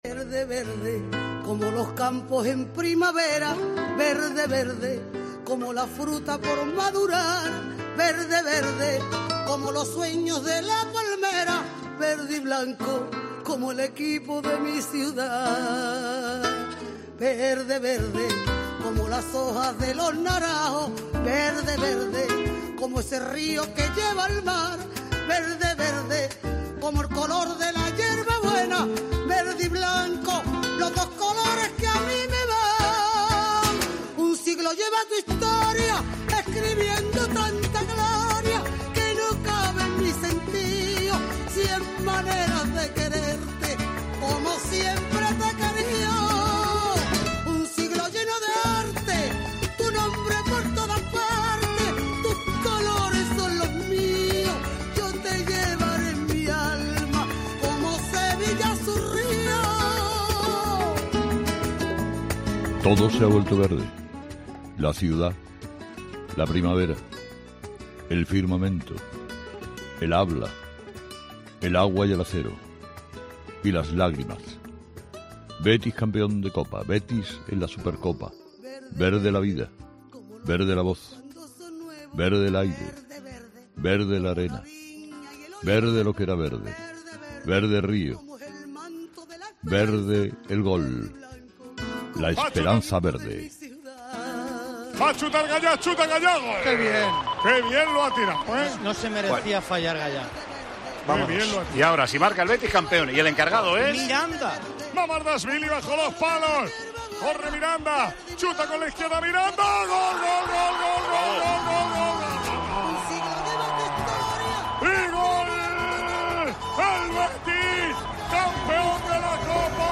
El presentador líder del prime time de la radio en España fue testigo privilegiado de la victoria de su equipo y pudo comprobar la entrega de algunos jugadores que, como Joaquín, lo dan todo por el escudo verdiblanco.
"Qué les voy a contar de lo que se ha vivido en Sevilla este fin de semana. Excelente afición del Valencia. Partido igualado, de poder a poder, un gran partido de fútbol. la lotería de los penaltis. Los dos merecieron vencer, pero al final el ganador fue el Betis. Esta noticia ha tapado otras de actualidad que sin duda deberemos comentar", ha comentado el presentador de 'Herrera en COPE', eufórico tras la victoria del Betis en la Copa del Rey.